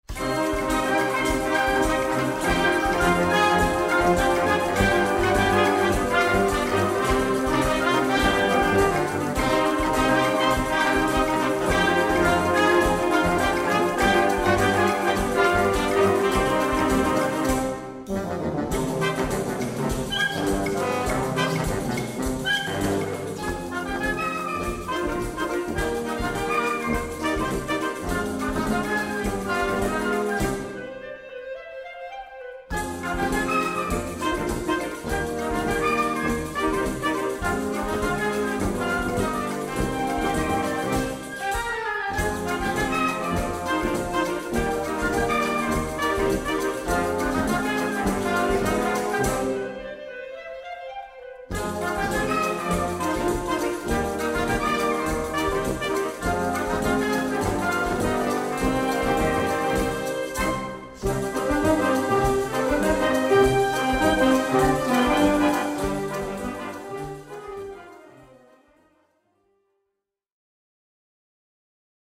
Gattung: Konzertstück
5:09 Minuten Besetzung: Blasorchester Zu hören auf